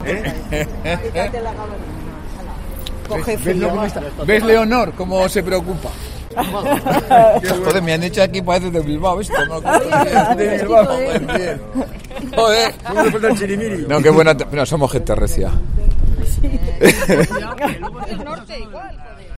Conrado Escobar, condidato a la alcaldía de Logroño, ha citado a los medios en el reloj de la plaza del Ayuntamiento.